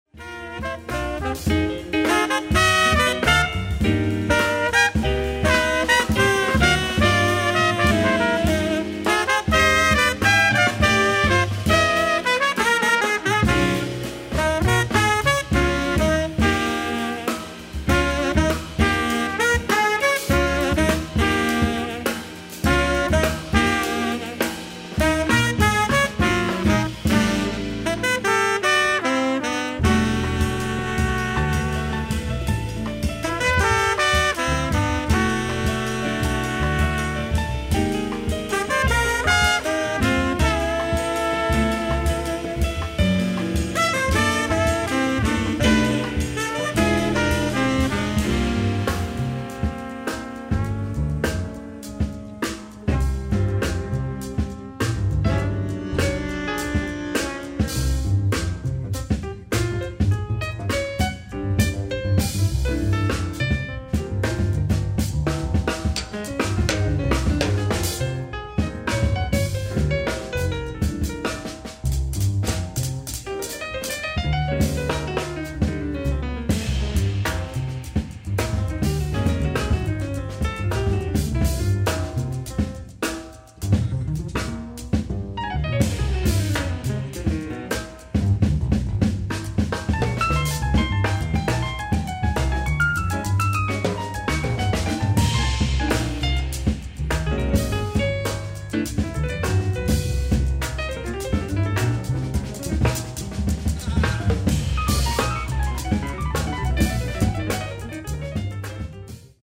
ライブ・アット・シュトゥットガルト、ドイツ 11/07/2019
しかも非公式とは思えない音質です 。
※試聴用に実際より音質を落としています。
ダニーロ・ロペス(p)